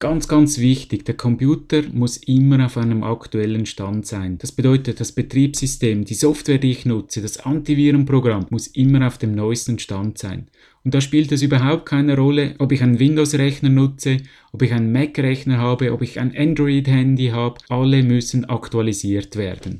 Rechts im Archiv gibt es das Interview auch in Schweizerdeutsch!!!